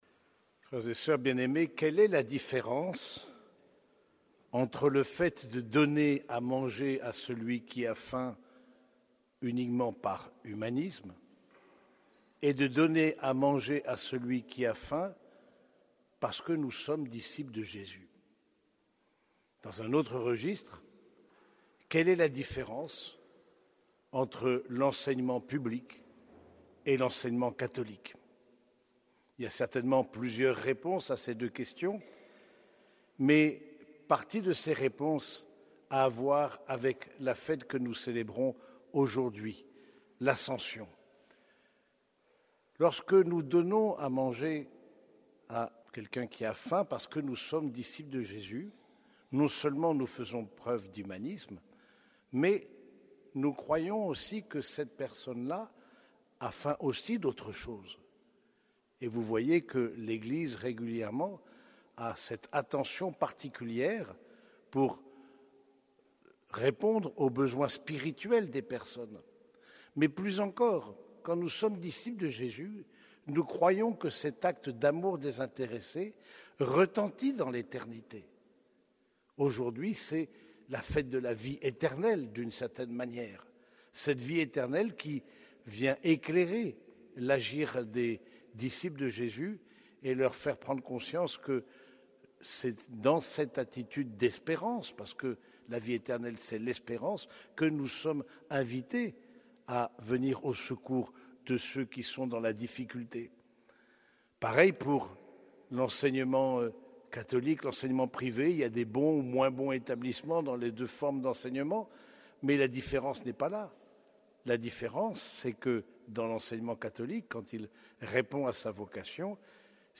Homélie de la solennité de l'Ascension du Seigneur